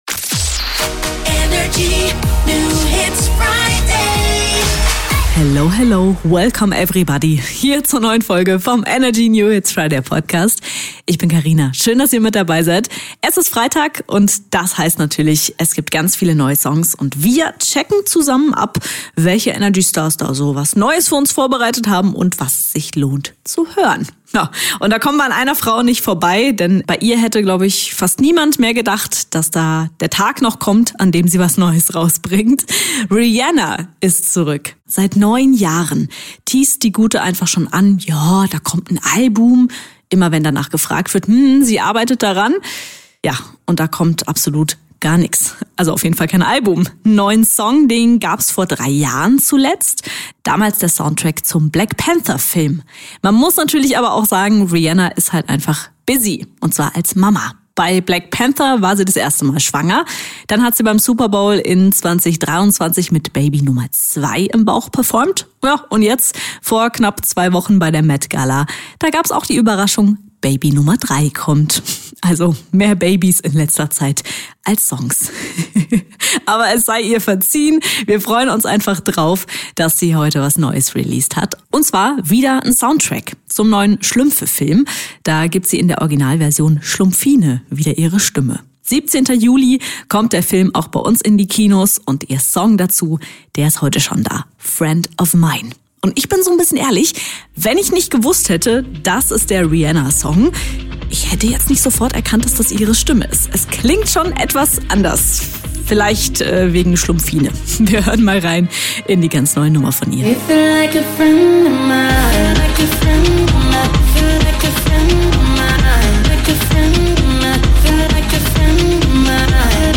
stellt brandneue hitverdächtige Songs vor.